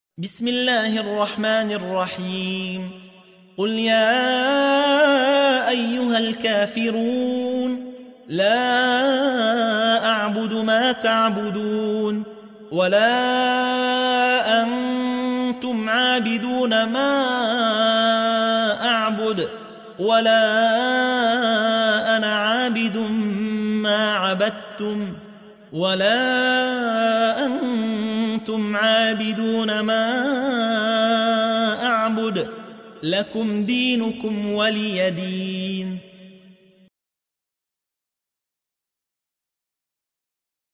تحميل : 109. سورة الكافرون / القارئ يحيى حوا / القرآن الكريم / موقع يا حسين